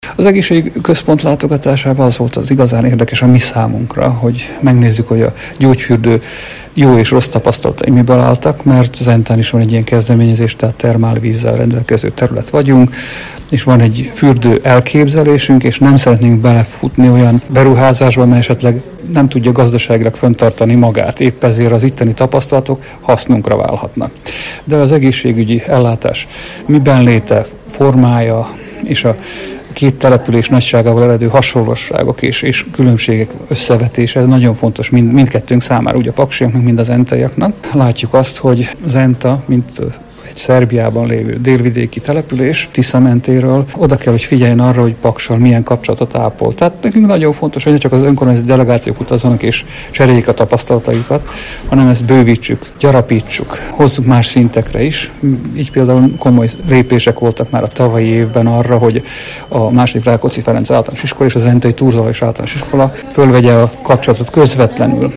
Juhász Attila, a zentai testvérvárosi delegáció vezetője az itteni tapasztalásukról beszélt rádiónknak.